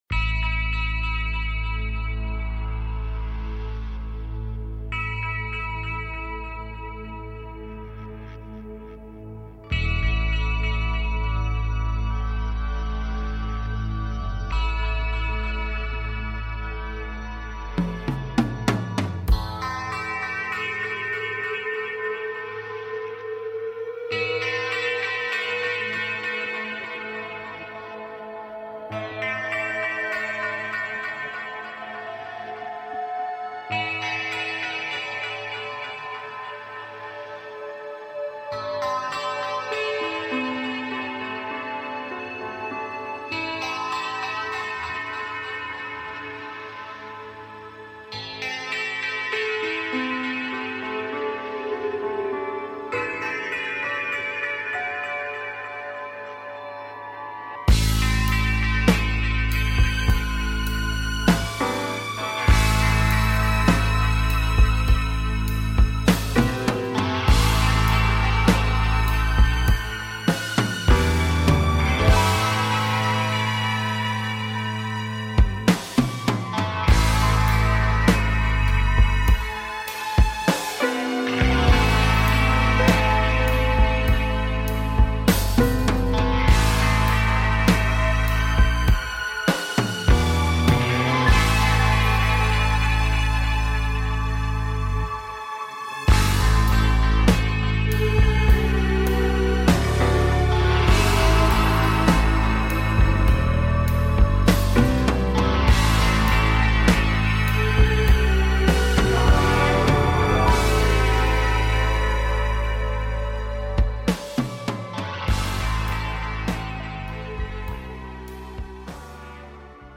Reviewing listener projects and answering listener calls